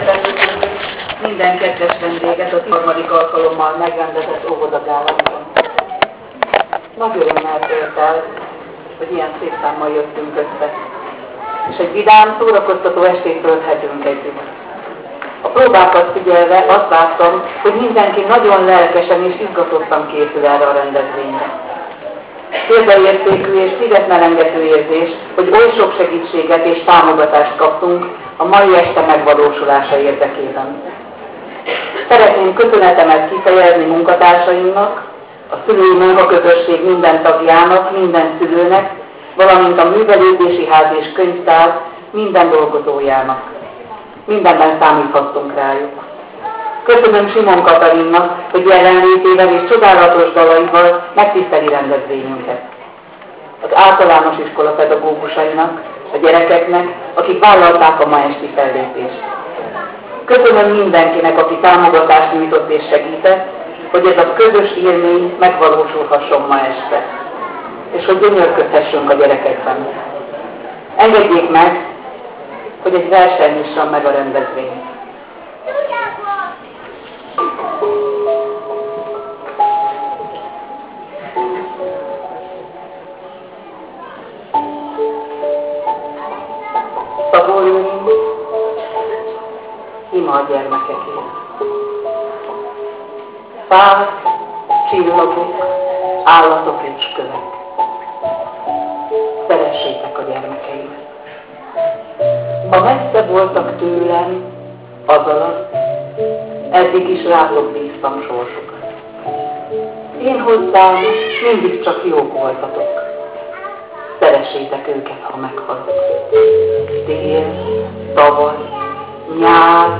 A kezdeményezésből hagyomány lett, így ebben az évben már 13. alkalommal gördült fel a függöny a színháztermet zsúfolásig megtöltő érdeklődők, szülők, kíváncsi vendégek előtt.